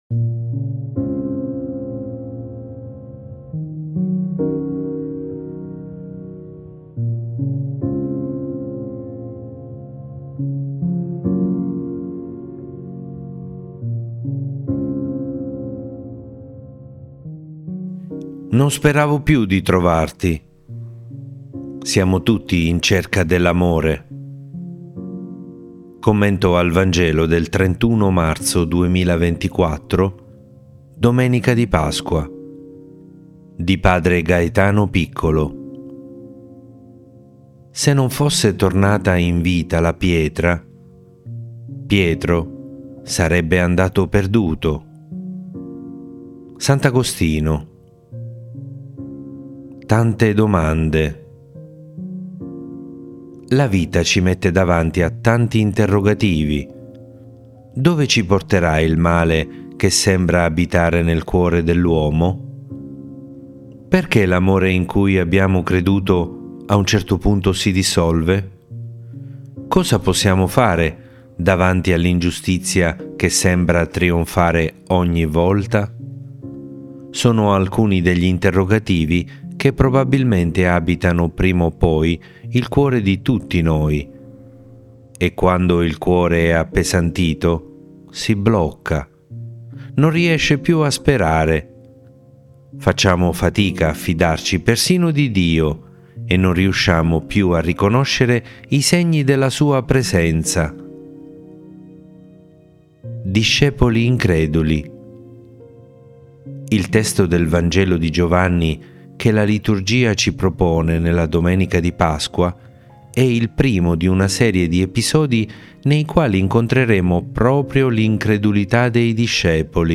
Non speravo più di trovarti! Siamo tutti in cerca dell’Amore. Commento al Vangelo del 30marzo 2024